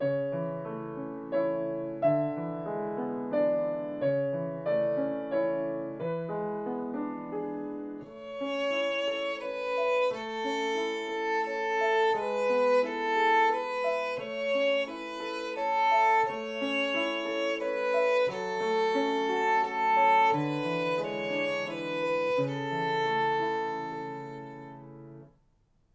♪♪♪練習のヒント♪♪♪ 三拍子の曲は弓のダウンアップによって音量の差が出ないように気を付けましょう。
ご自宅での練習用に録音しました。